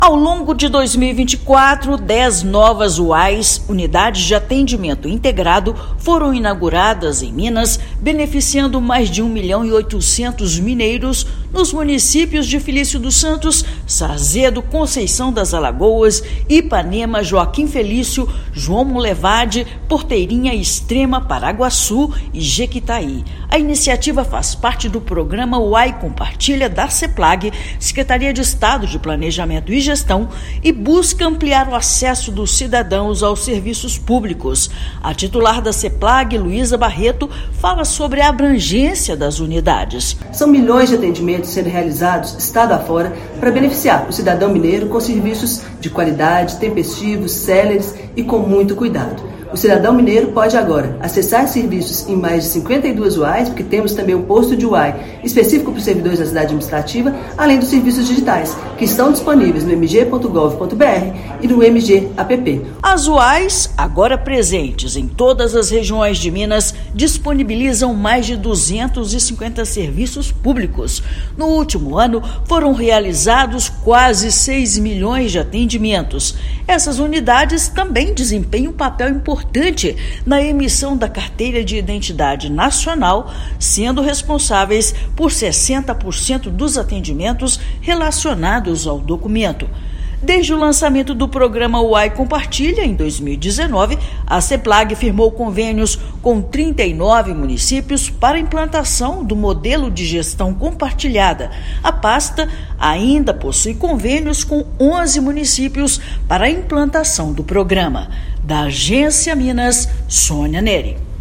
Com dez novas unidades em 2024, UAIs ampliam serviços públicos a mais de 1,8 milhão de mineiros, reforçando cidadania e acesso em todo o estado. Ouça matéria de rádio.